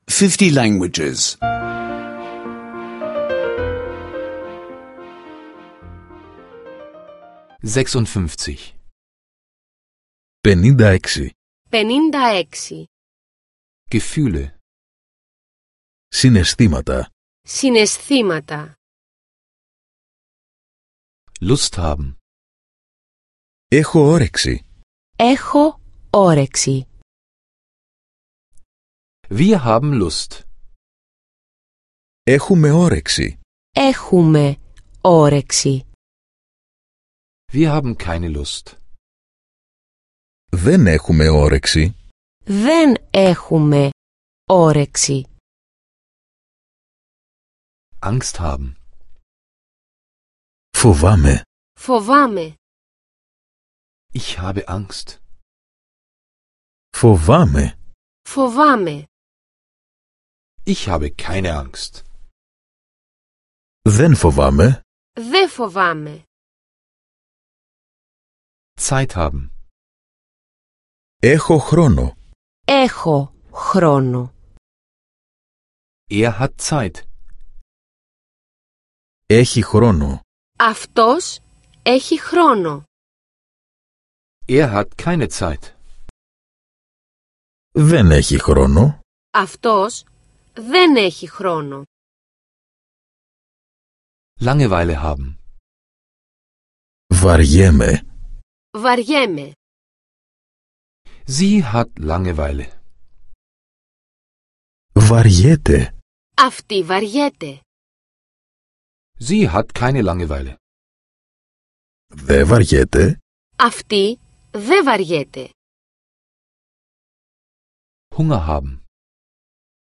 Griechisch Audio-Lektionen, die Sie kostenlos online anhören können.